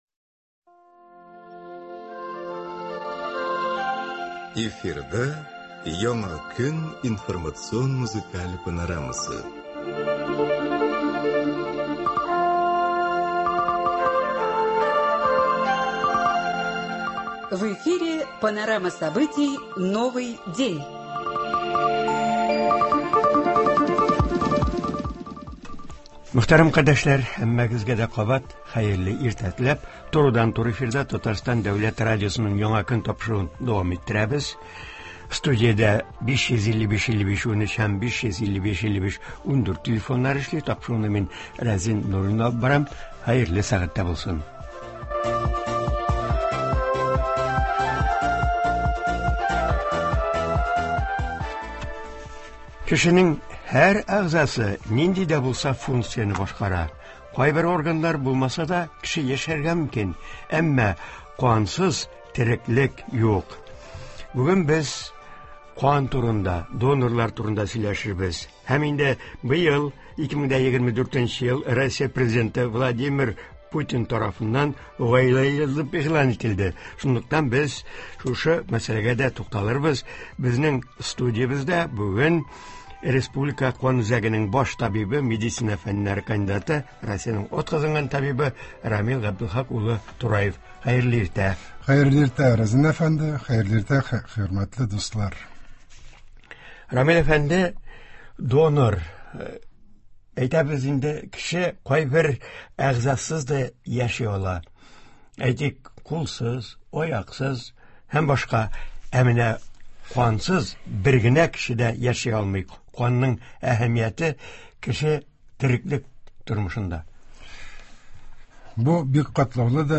Туры эфир (22.05.24) | Вести Татарстан
Тыңлаучылар игътибрына аның белән әңгәмә тәкъдим ителә.